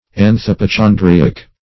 Anthypochondriac \Ant`hyp*o*chon"dri*ac\
anthypochondriac.mp3